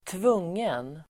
Uttal: [²tv'ung:en]